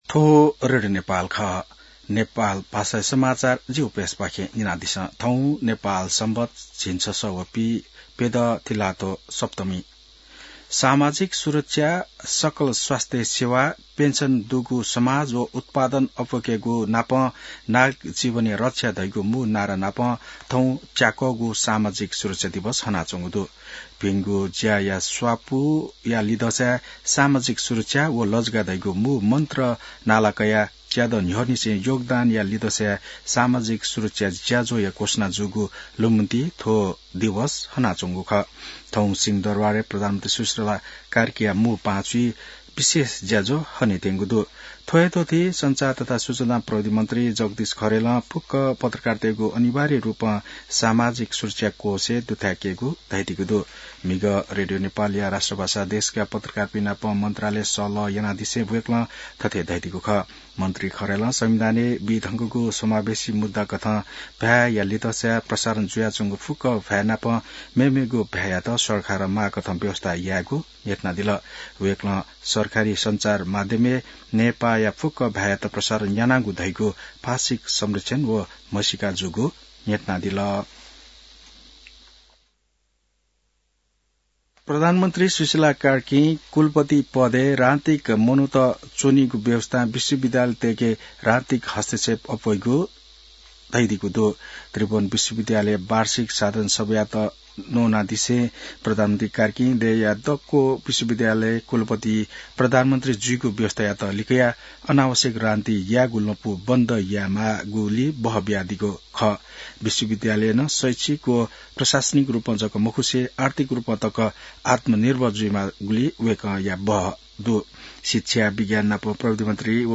नेपाल भाषामा समाचार : ११ मंसिर , २०८२